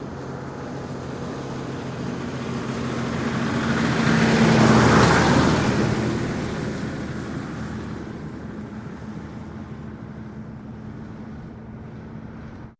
Truck, Hino Diesel, Pass By, Slow